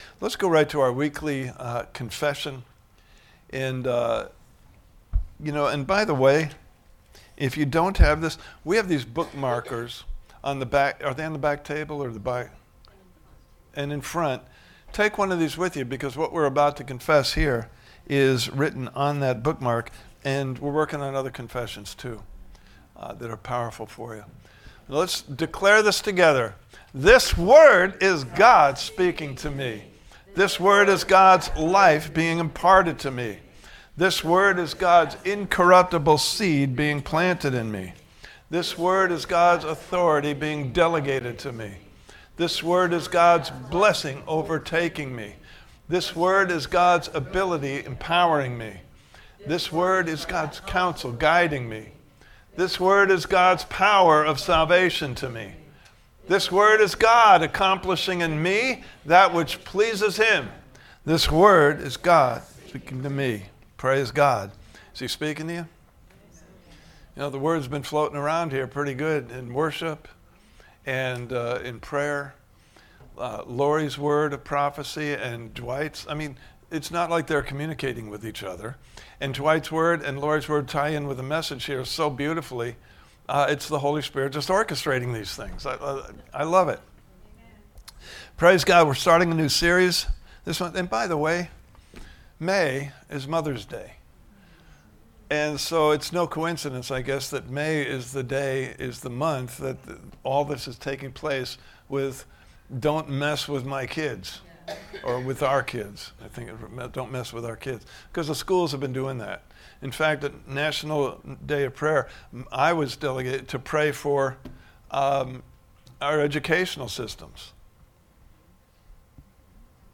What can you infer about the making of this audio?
Standing on a Firm Foundation Service Type: Sunday Morning Service « Part 4